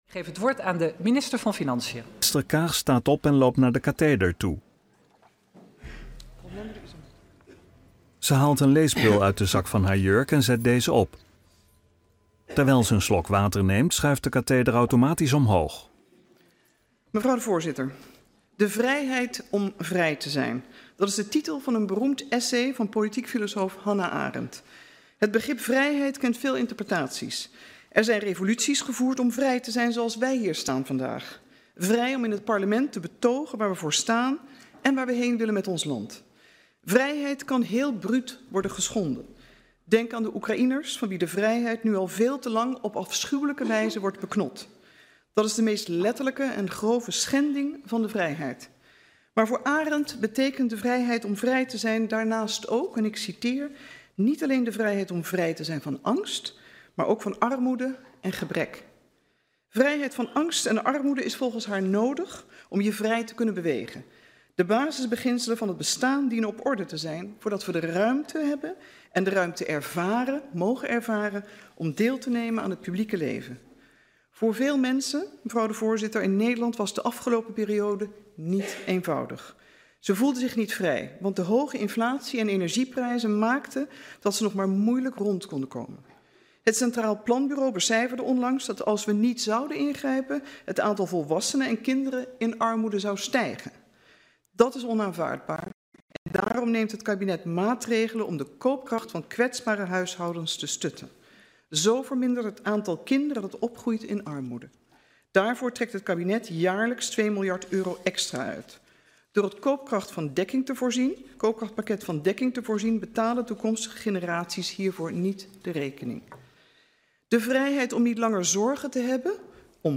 Toespraak van minister Kaag (Financiën) bij de aanbieding van de Miljoenennota 2024 aan de Tweede Kamer op 19 september 2023 in Den Haag.